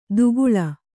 ♪ duguḷa